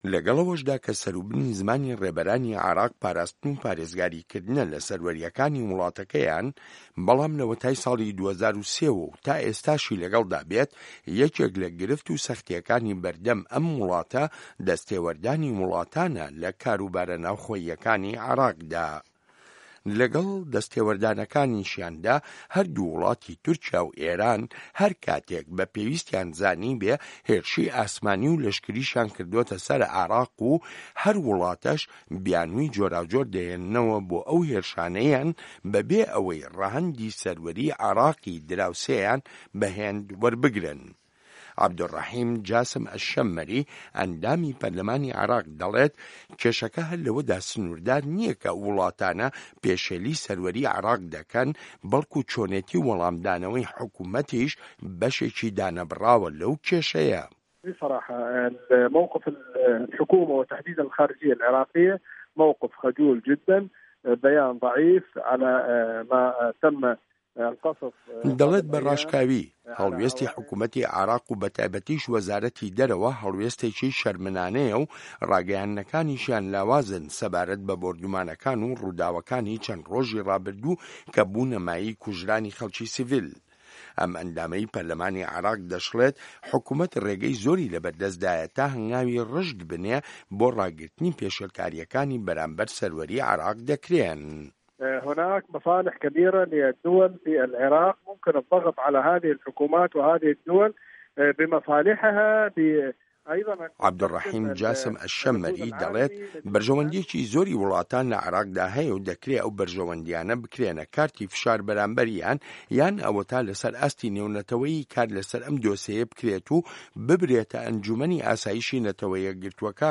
ڕاپۆرتی سەروەری عێراق لە ڕوانگەی وڵاتە دراوسێکانیەوە